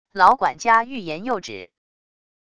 老管家欲言又止wav音频